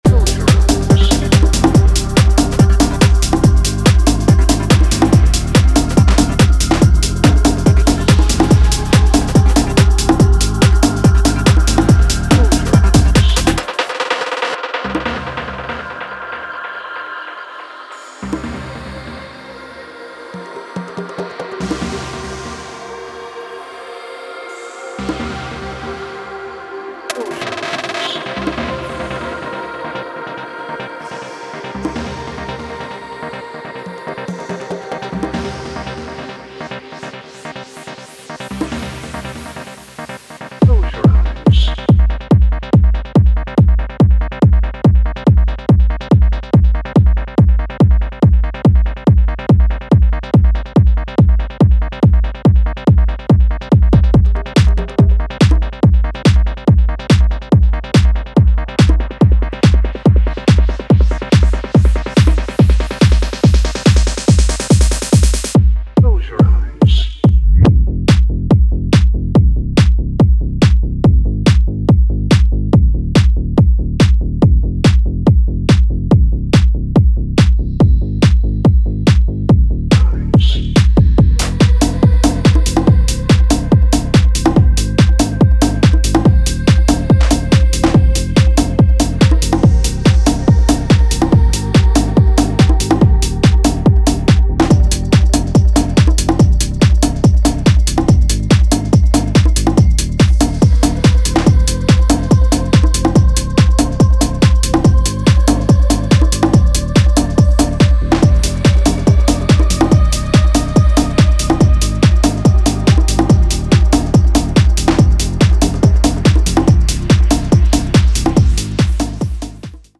ボンゴ/コンガの軽快なグルーヴ、ヴォリュームたっぷりなローエンドのうねり、神秘的なヴォーカル